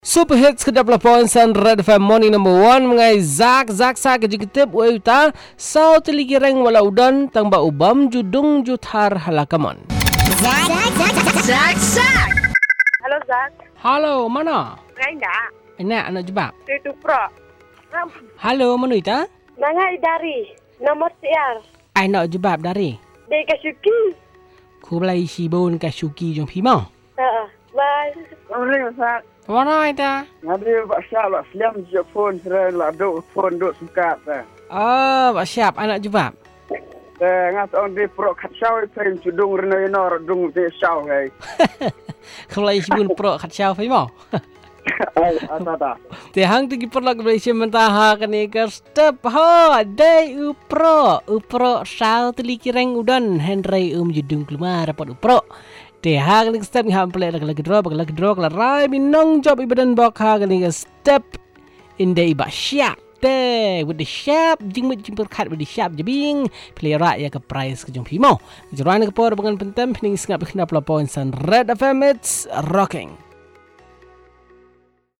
Calls and Result